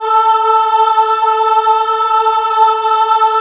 A3 WOM AH -L.wav